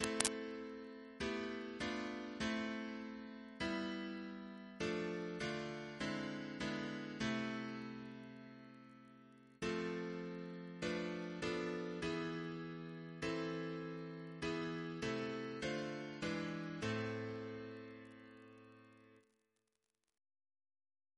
Double chant in A minor Composer: Walter Biery (b.1958)